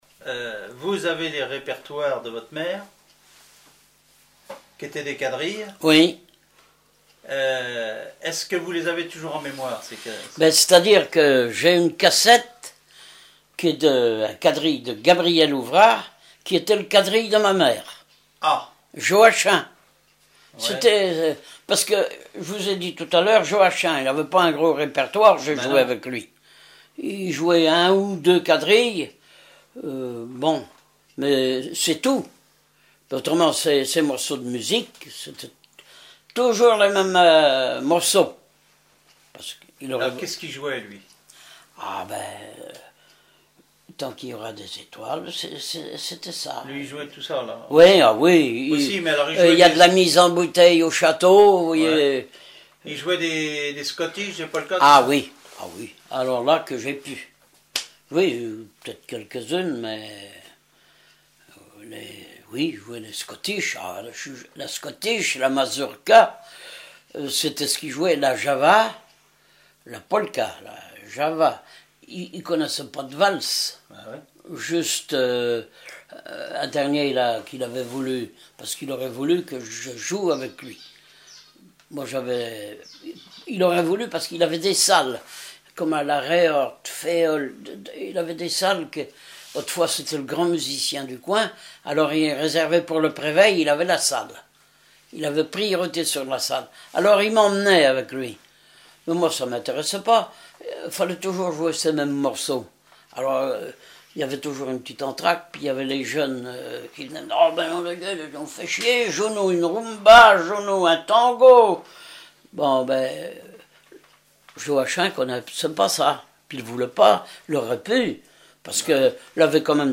Enquête Compagnons d'EthnoDoc - Arexcpo en Vendée
Catégorie Témoignage